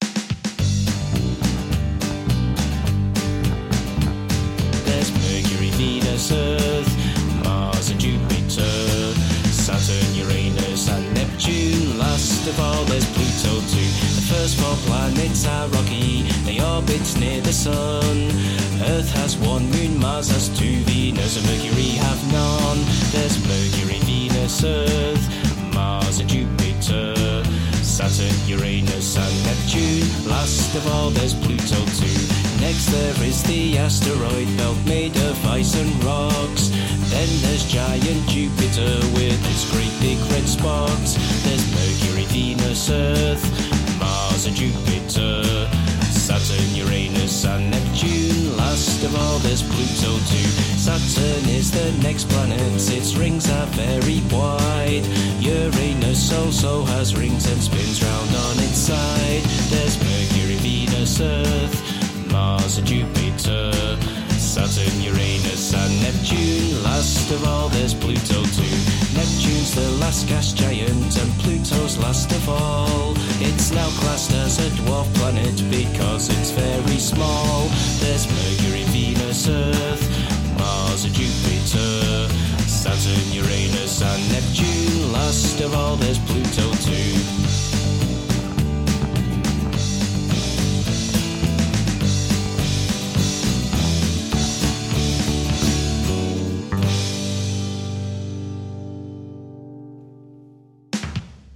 This little ditty is a song a good friend of mine wrote for his (at the time) 2 year old daughter who was showing an interest in space!